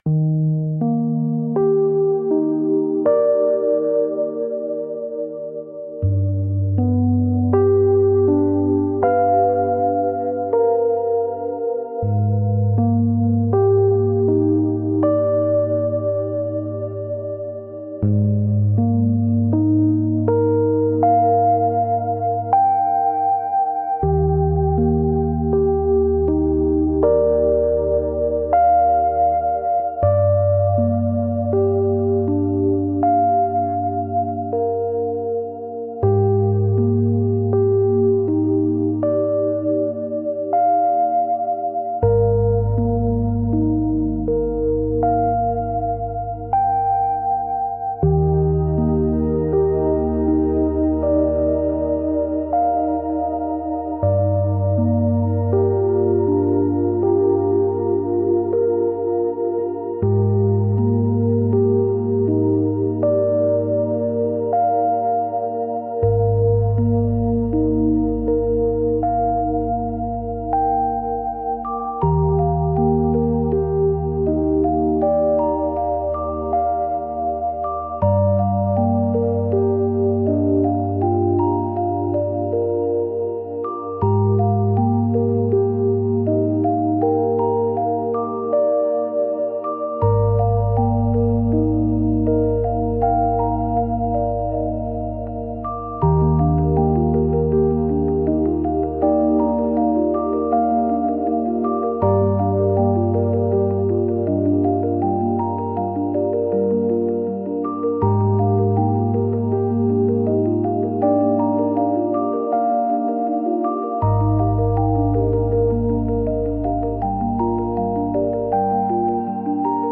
「幻想的」